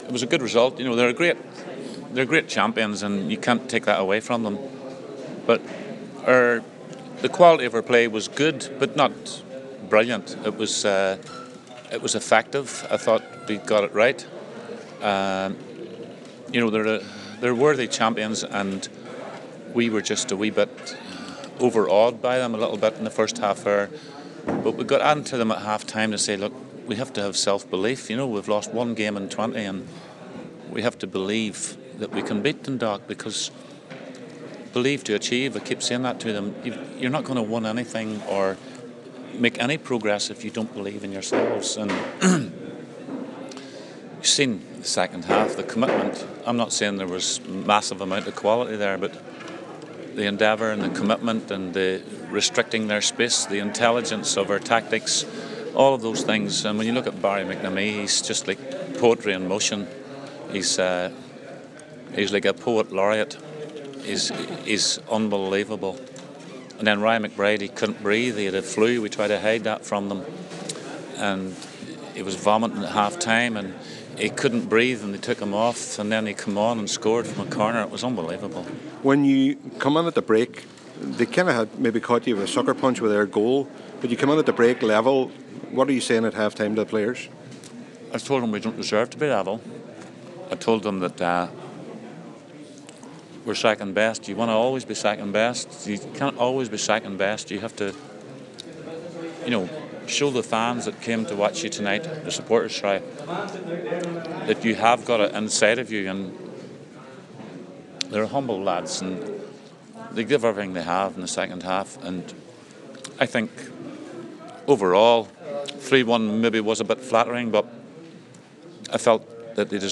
Derry Manager Kenny Shiels says his side deserved the win…